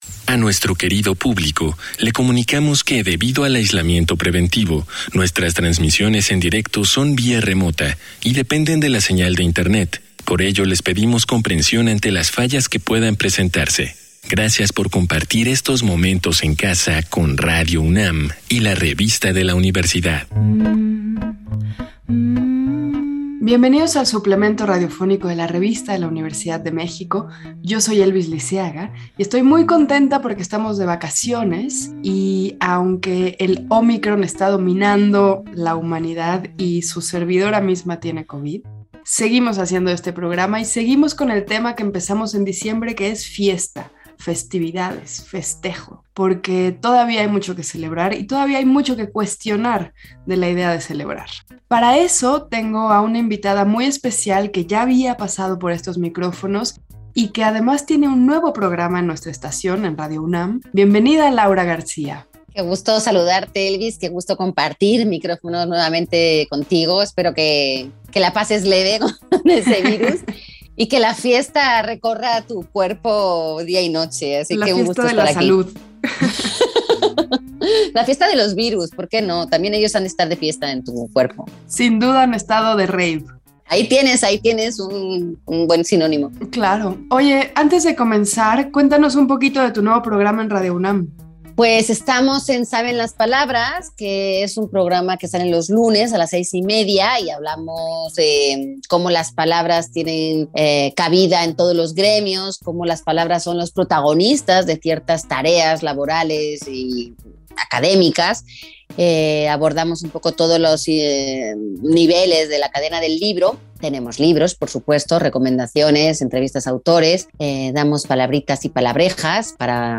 Fue transmitido el jueves 20 de enero de 2022 por el 96.1 FM.